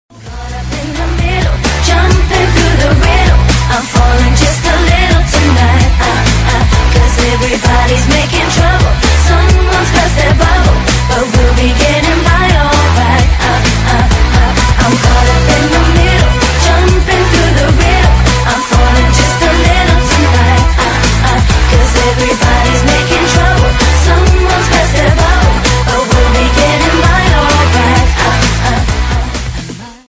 分类: DJ铃声
慢摇+超强打碟